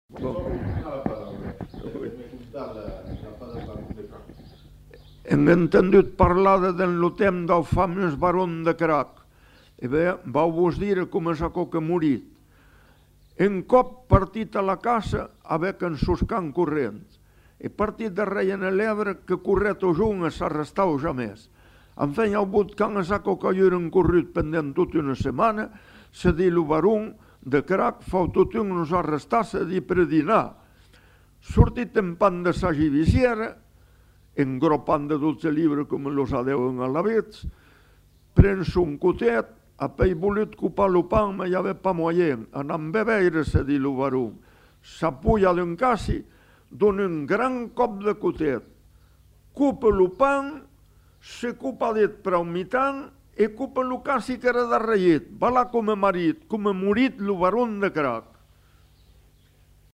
Lieu : Saint-Yzans-de-Médoc
Genre : conte-légende-récit
Effectif : 1
Type de voix : voix d'homme
Production du son : parlé
Classification : récit légendaire